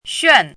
xuàn
拼音： xuàn
注音： ㄒㄩㄢˋ
xuan4.mp3